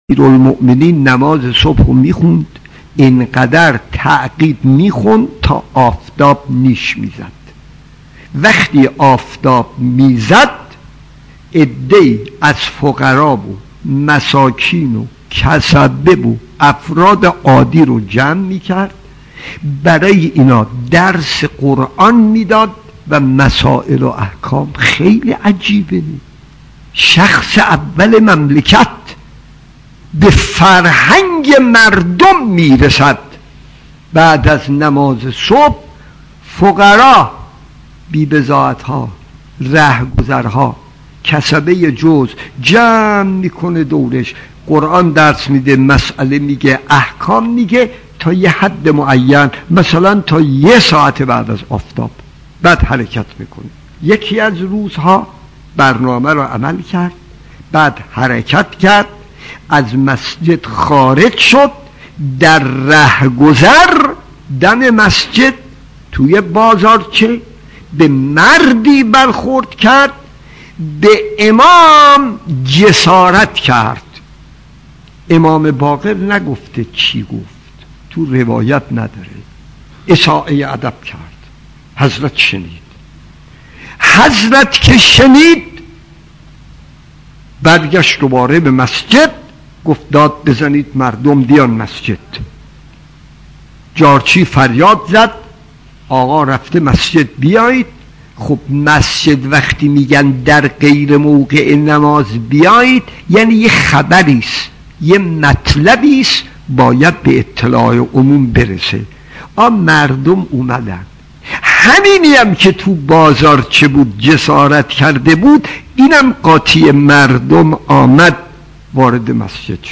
داستان 6 : کسی به امام علی جسارت نمود خطیب: استاد فلسفی مدت زمان: 00:07:02